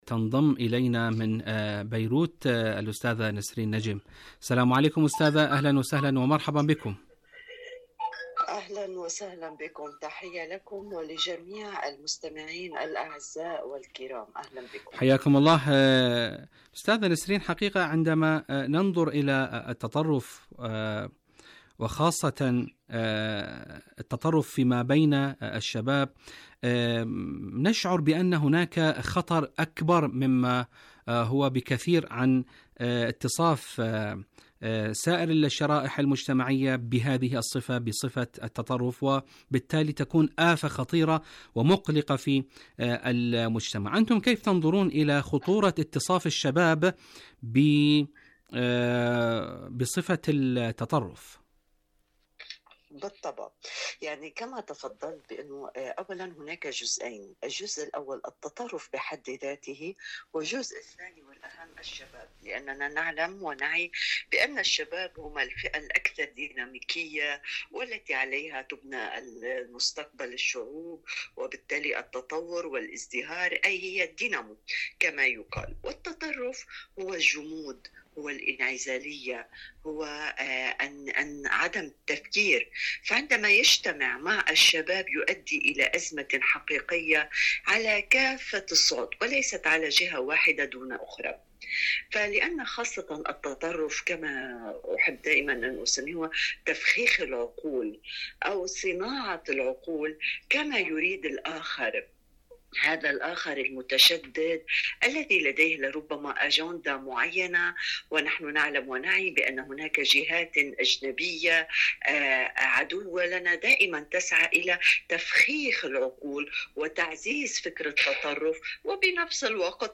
مقابلات برامج إذاعة طهران العربية مقابلات إذاعية الفكر المتطرف آفة في مجتمع الشباب الفكر المتطرف آفة في مجتمع الشباب الوقاية من التطرف الفكر المتطرف لدى الشباب ما معنى الفكر المتطرف؟ التطرف الفكري وأثره على هوية الشباب الشباب والفكر المتطرف برنامج دنيا الشباب شاركوا هذا الخبر مع أصدقائكم ذات صلة من غزة إلى جنوب لبنان، الحاضنة الشعبية والتصدي للاحتلال..